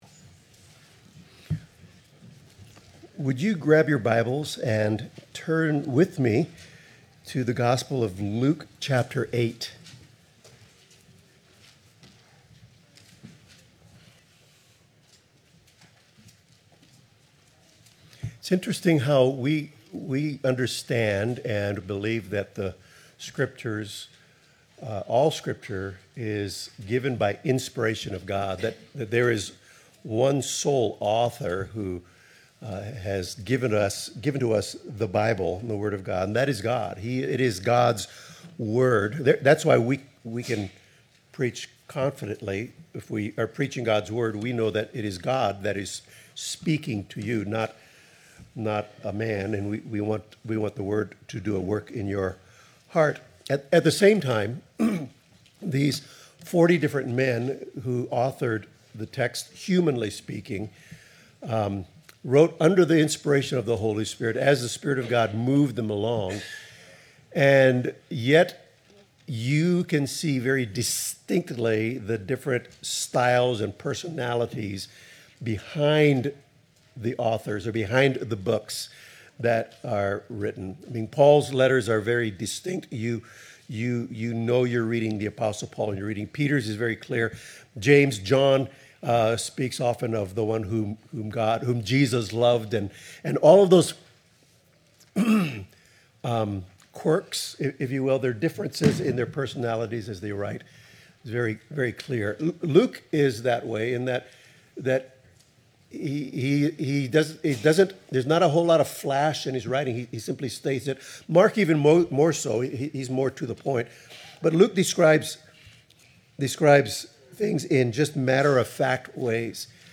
Luke 8:22-39 Service Type: Morning Service Jesus is the God over creation and exercises authority over it .